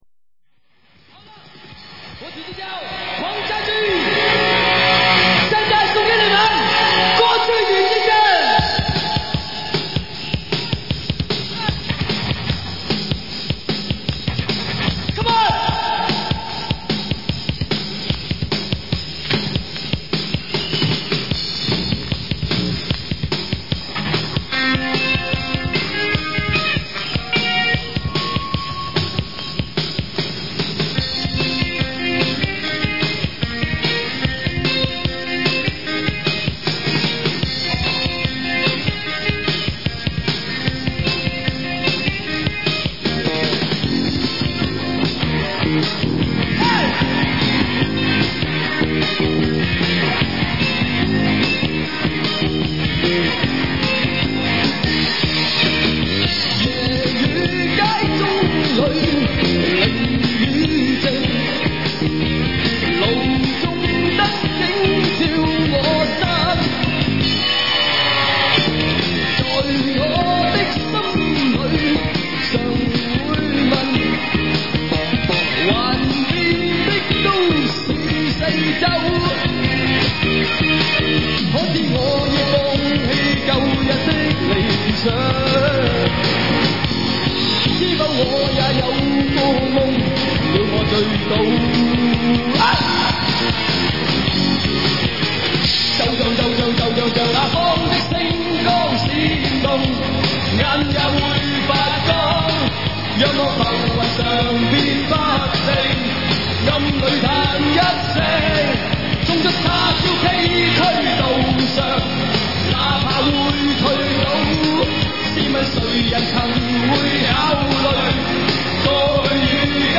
1986年【台北演唱会】